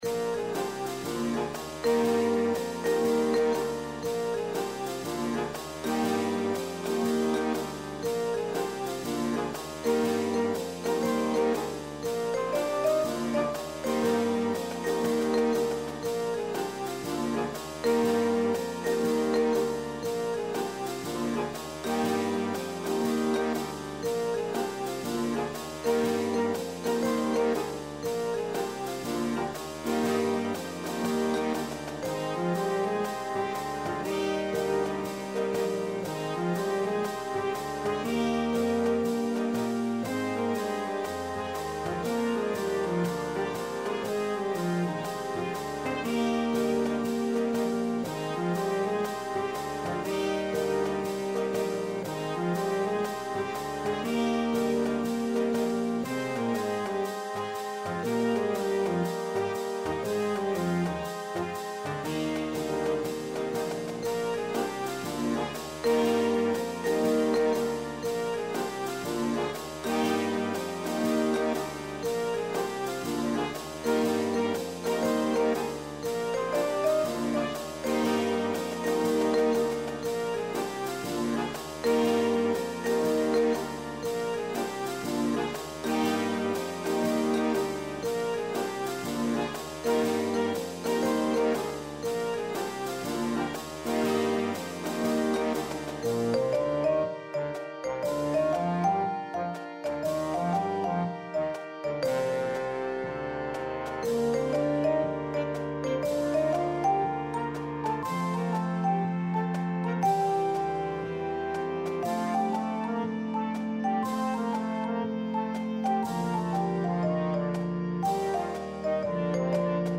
a superb blend of swing and popular styles